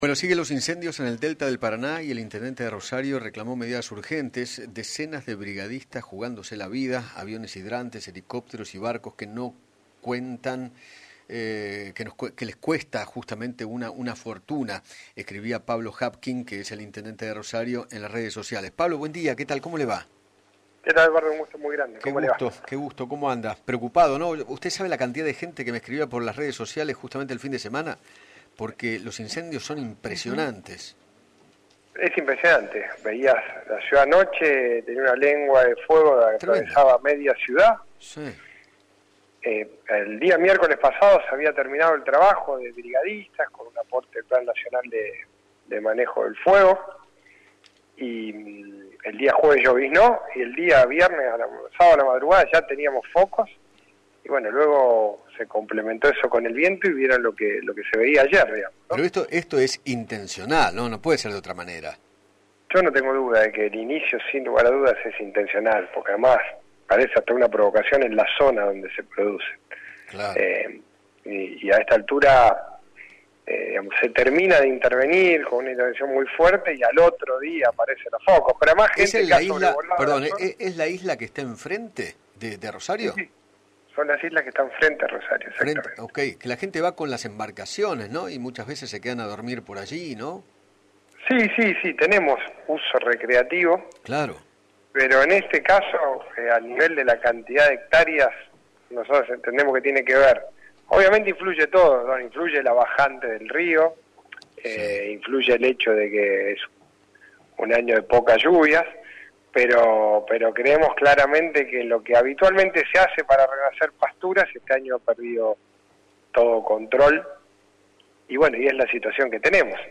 Pablo Javkin, Intendente de Rosario, dialogó con Eduardo Feinmann sobre los incendios en el Delta del Paraná, que volvieron a tomar fuerza durante el fin de semana, y confirmó que hizo “una denuncia penal y se iniciaron indagatorias”.